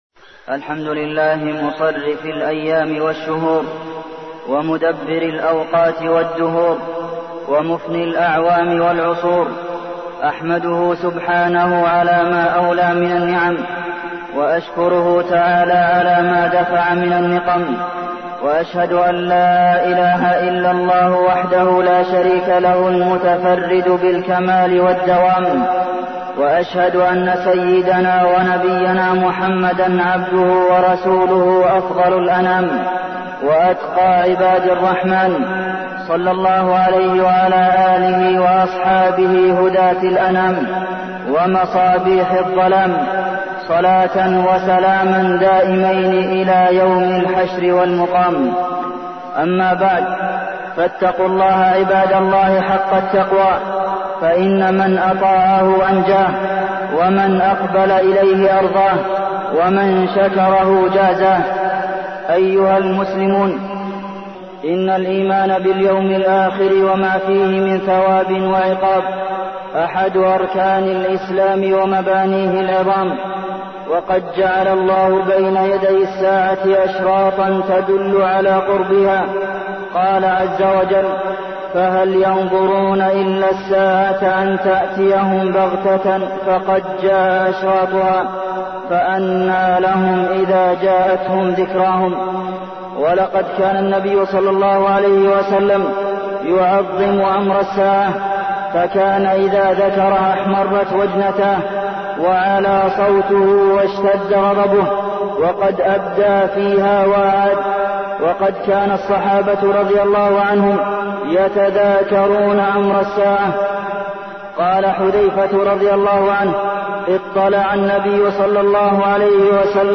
تاريخ النشر ٣ ذو القعدة ١٤١٩ هـ المكان: المسجد النبوي الشيخ: فضيلة الشيخ د. عبدالمحسن بن محمد القاسم فضيلة الشيخ د. عبدالمحسن بن محمد القاسم أشراط الساعة